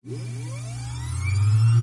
d0 温和的计算机嗡嗡声与硬盘驱动器访问
描述：一个温和的循环电脑风扇，一个非常安静的变压器嗡嗡声和偶尔的硬盘访问。
标签： 磁盘 硬盘 硬盘 风扇 电脑 风扇 PC 访问驱动器 硬盘 嗡嗡声 ATMO 噪声 硬盘驱动器
声道立体声